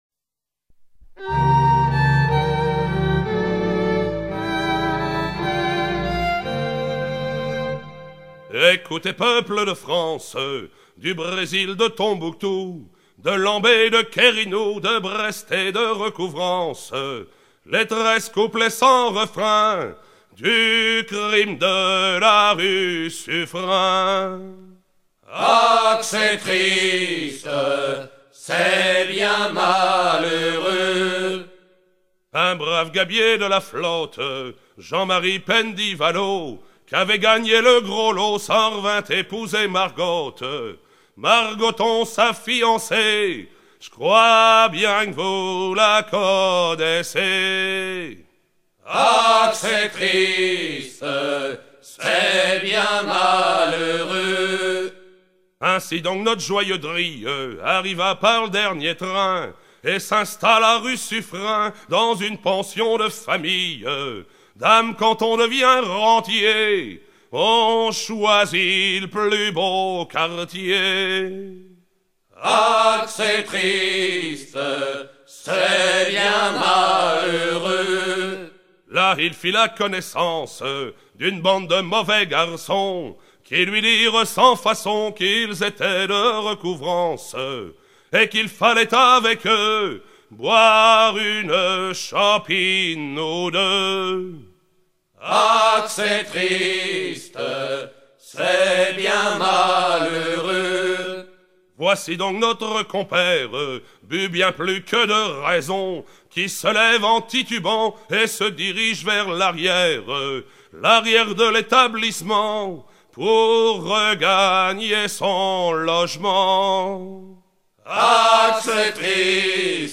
Chanson
Pièce musicale éditée